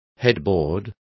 Complete with pronunciation of the translation of headboards.